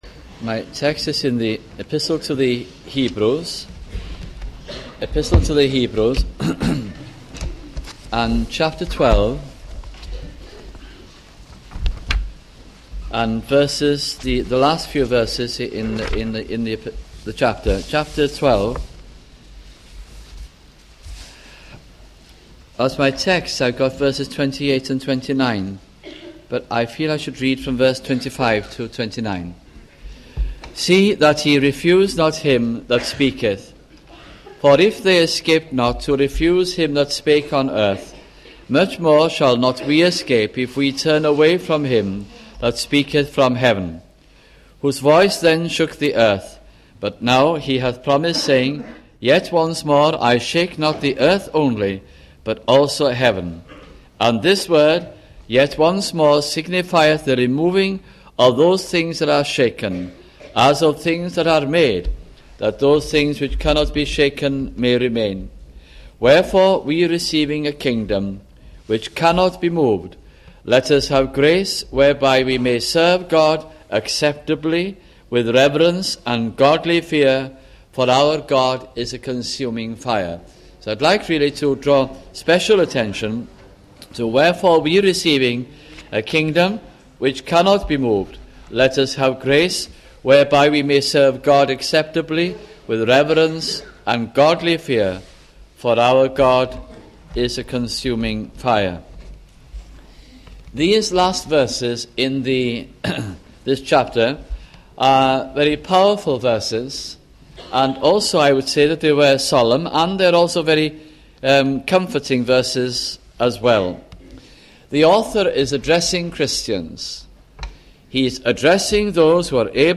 » The Epistle to the Hebrews 1984 - 1986 » sunday morning messages